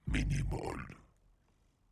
• demonic techno voice "minimal".wav
Changing the pitch and transient for a studio recorded voice (recorded with Steinberg ST66), to sound demonic/robotic.